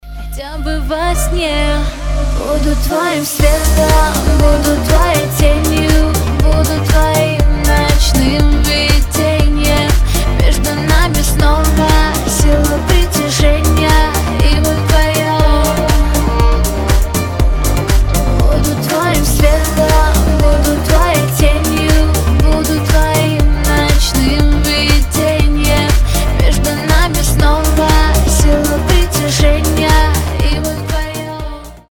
• Качество: 320, Stereo
поп
женский вокал
dance
club